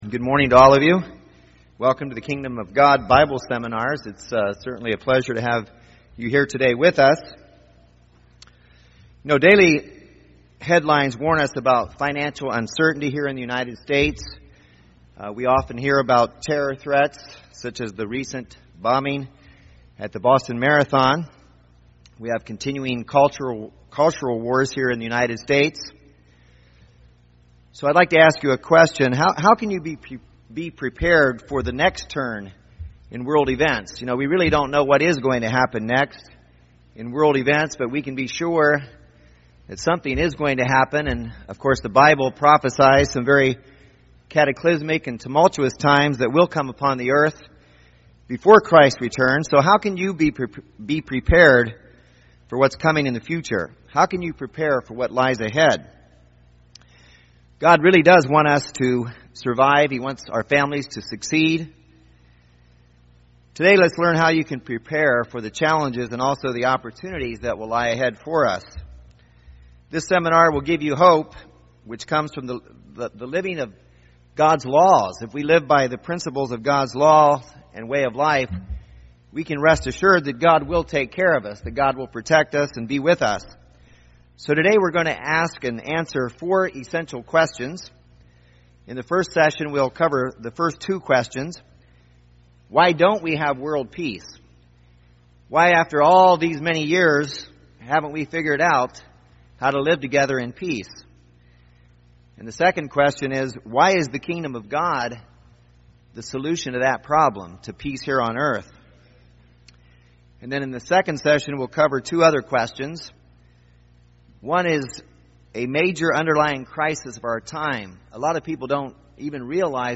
Kingdom of God Bible Seminar Series, Part 5, Session 1 Just what is the Gospel of the Kingdom of God?